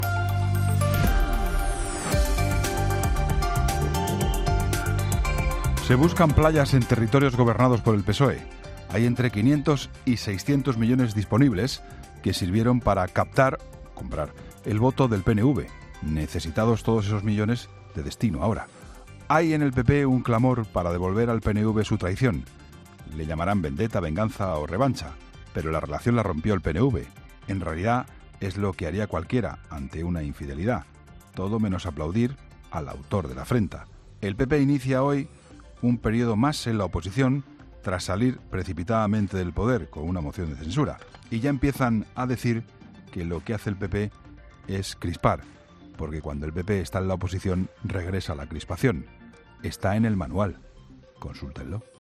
Escucha el comentario del director de 'La Linterna', Juan Pablo Colmenarejo, en 'Herrera en COPE'